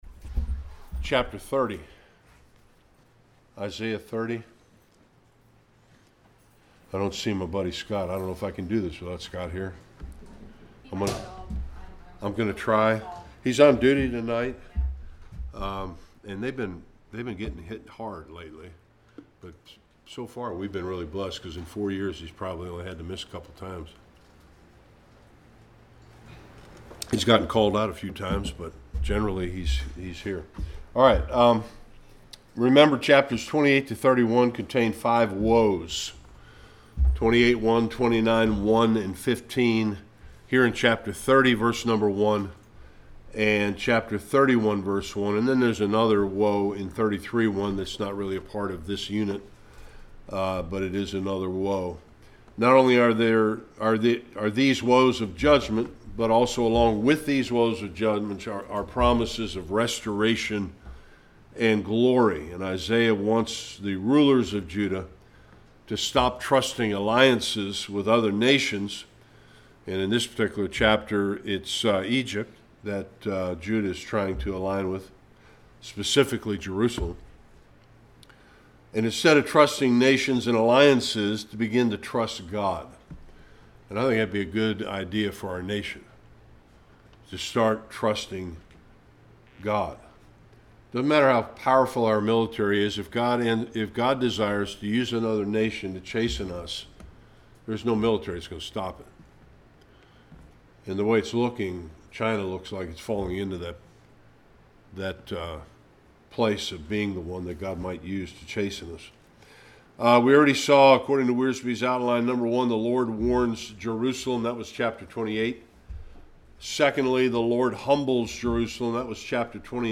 1-33 Service Type: Bible Study Chapter 30 begins with another woe pointed specifically at Jerusalem.